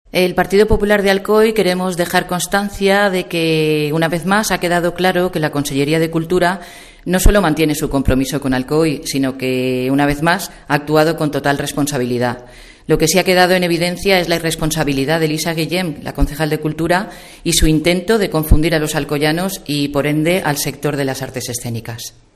Isabel Balaguer, edil del PP: "En el PP de Alcoy queremos dejar constancia de que, una vez más, la Conselleria de Cultura no solo mantiene el compromiso con Alcoy sino que, una vez más, ha actuado con total responsabilidad"